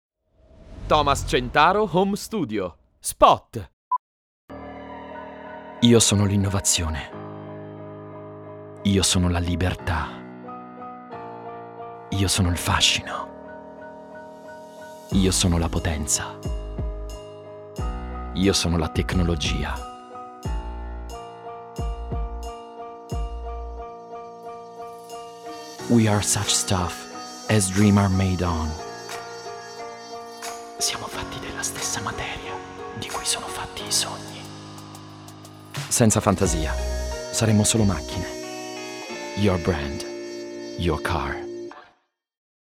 ITALIAN MALE VOICE OVER ARTIST
I own the latest technology equipment wrapped in a soundproof environment for high quality professional recordings that guarantee a result in line with the prestige of the brand to be promoted.
COMMERCIAL